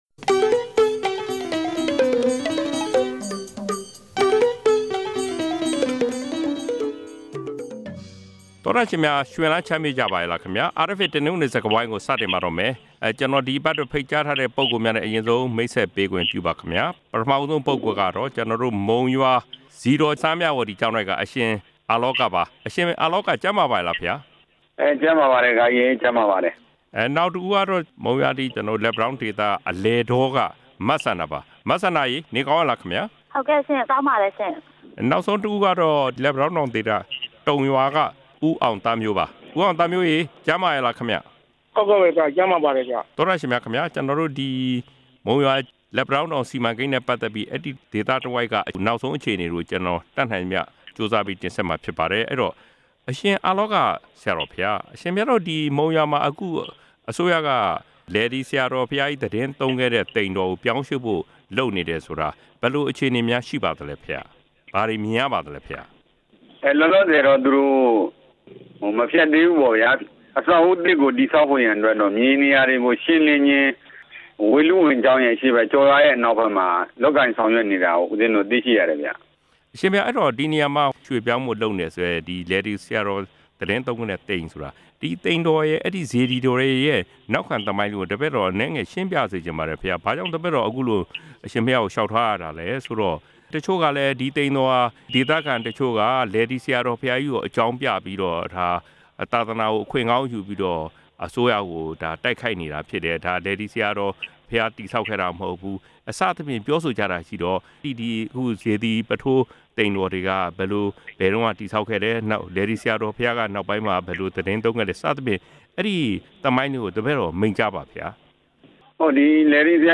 ဒီအပတ် တနင်္ဂနွေ စကားဝိုင်းမှာ လက်ပံတောင်းတောင် ဒေသခံပြည်သူတွေရဲ့ လက်ရှိဘဝနဲ့ သဘောထားအမြင်တွေကို နားဆင်ရမှာဖြစ်ပါတယ်။